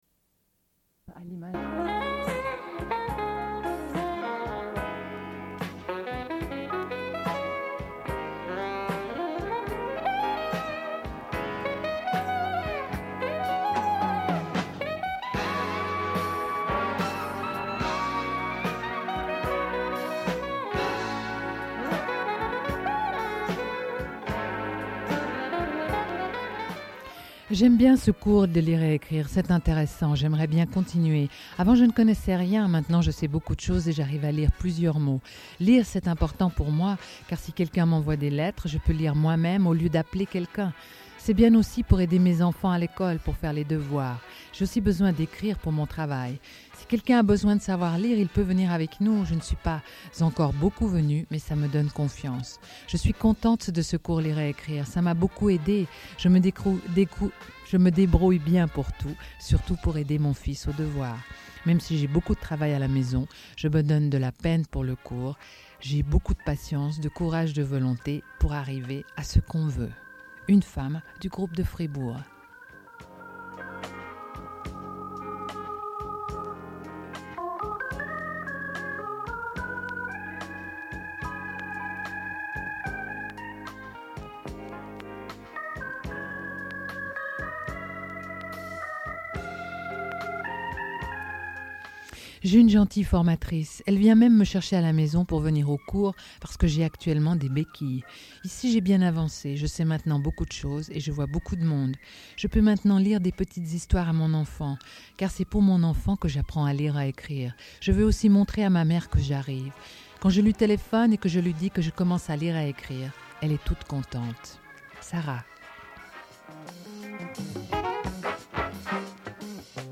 Lecture du texte du personnel.
Radio Enregistrement sonore